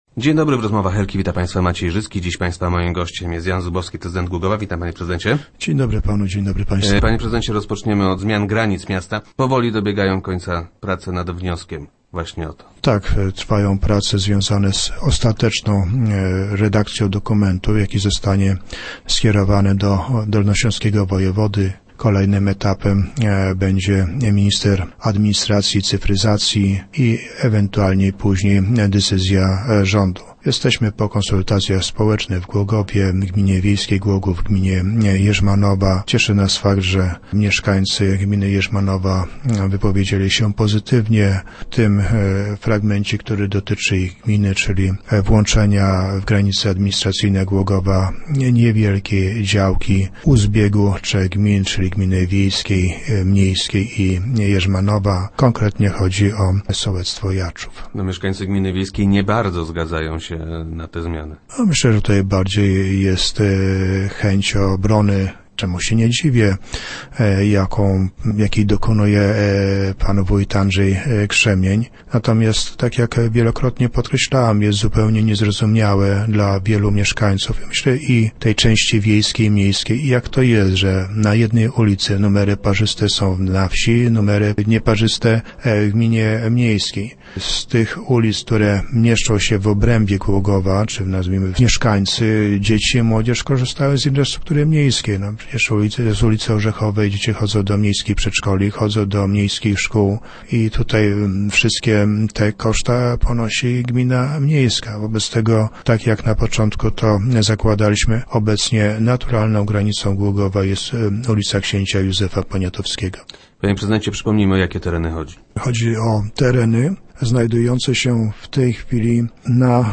- Nasz wniosek jest już na ukończeniu - informuje prezydent Jan Zubowski, który był gościem Rozmów Elki.